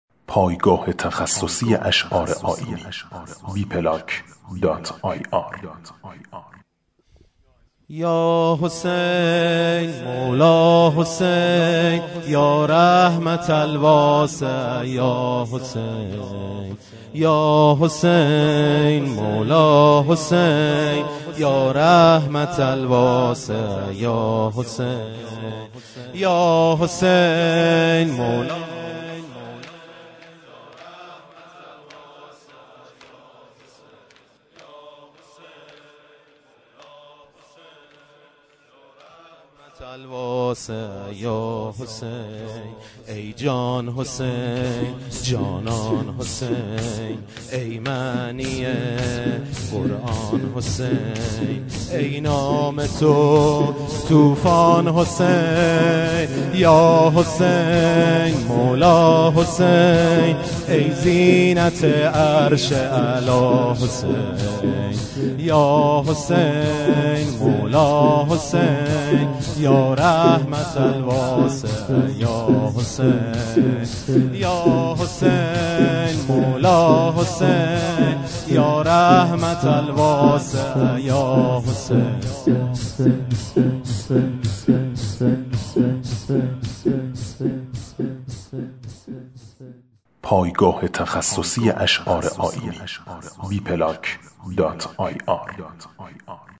شور حضرت امام حسین علیه السلام